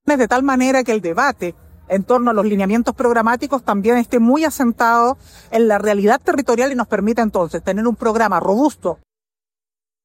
En tanto, la también integrante del comando de Jara y secretaria General del Partido Comunista, Bárbara Figueroa, afirmó este sábado, durante el evento que celebró el aniversario número 93 de las Juventudes Comunistas, que se ha trabajado en una escucha activa en los distintos territorios.